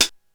Index of /90_sSampleCDs/300 Drum Machines/Korg DSS-1/Drums03/01
HihatCl.wav